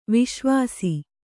♪ viśvāsi